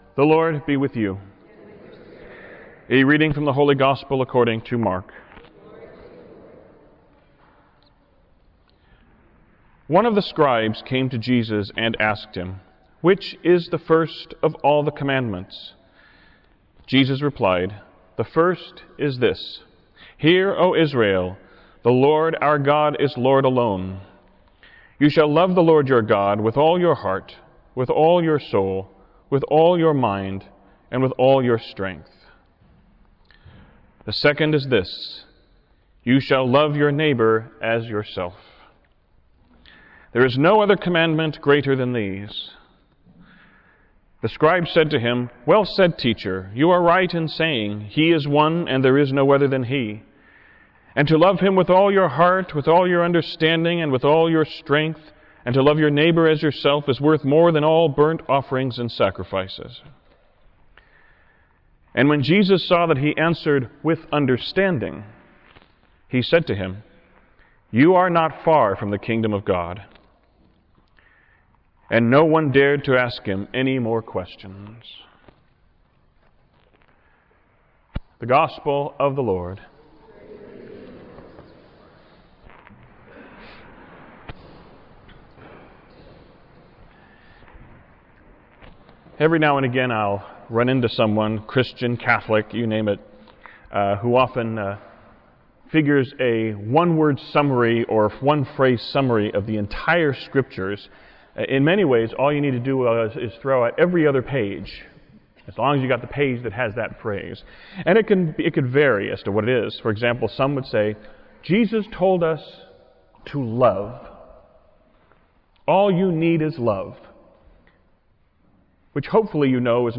Homily-31stSundayBGreatestCommandment.wav